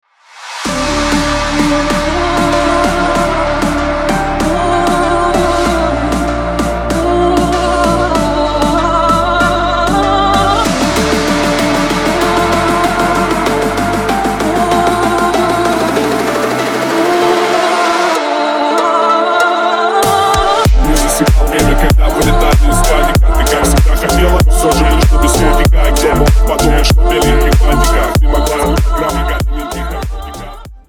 Рэп и Хип Хоп
клубные